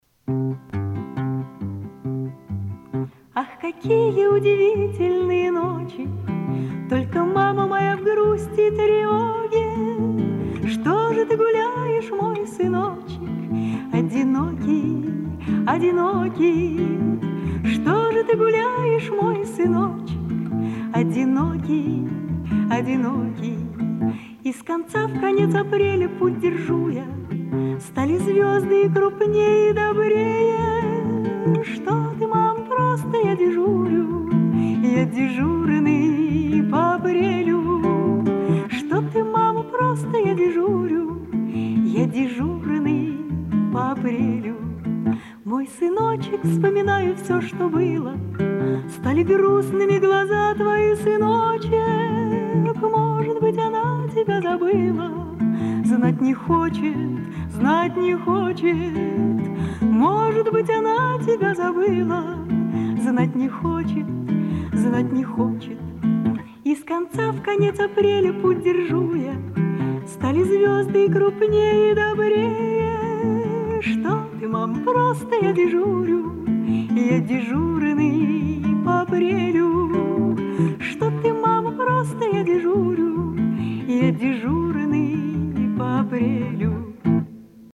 На концерте в доме Окуджавы.